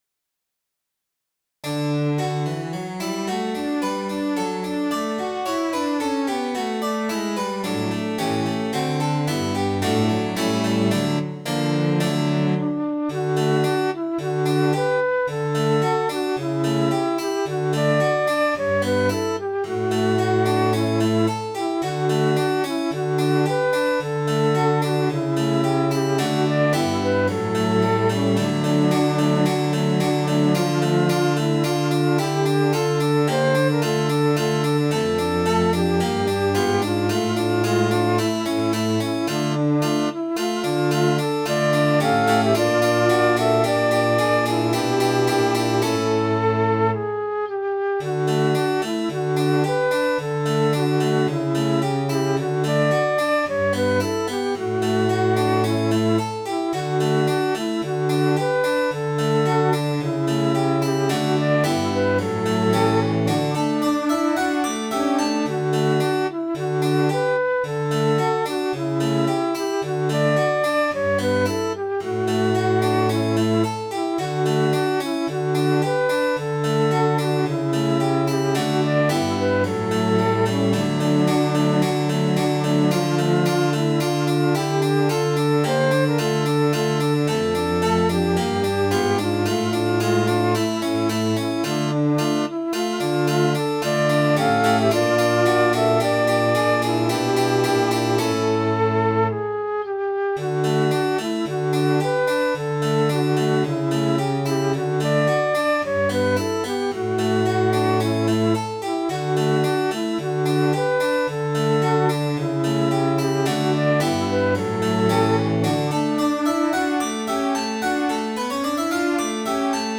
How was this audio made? silkattire.mid.ogg